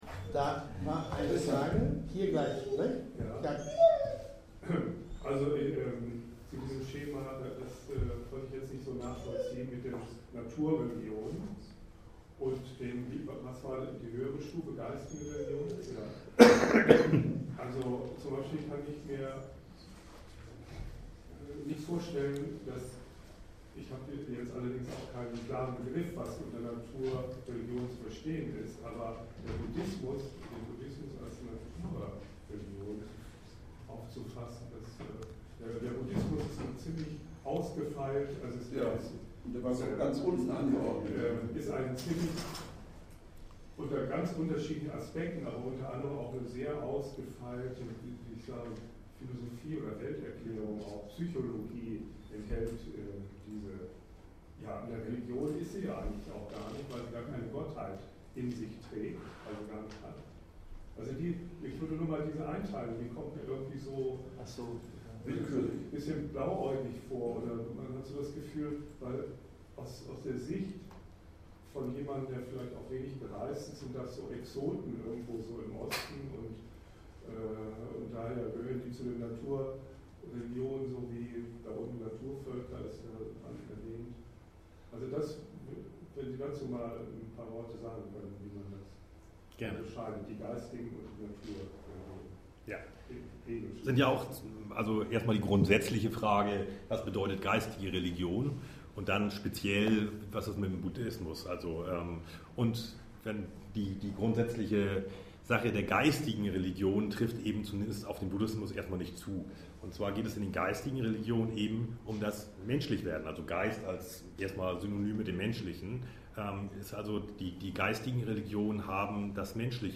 Einführung in Hegels Philosophie (Audio-Vortrag)
Sokrates-Vereinigung in Hamburg am 22.6.2011